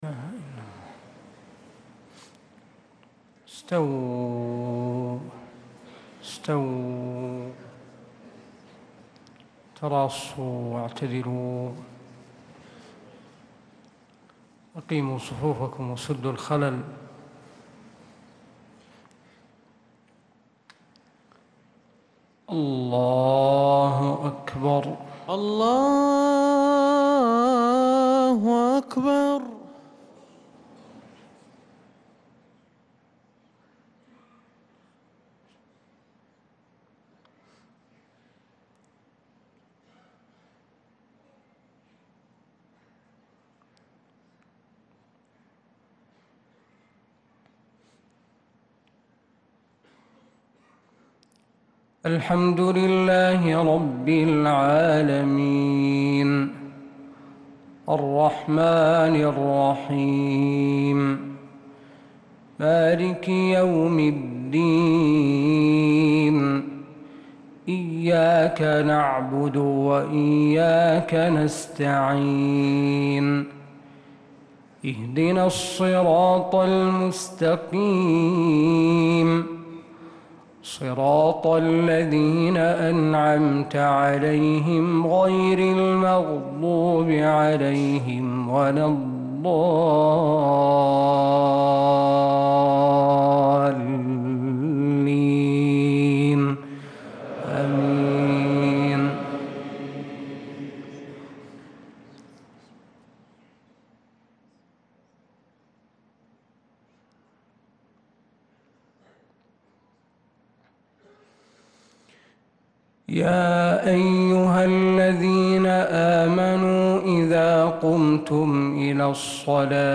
تِلَاوَات الْحَرَمَيْن .